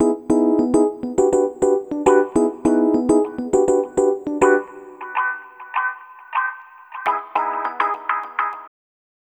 Ala Brzl 1 Piano-C#.wav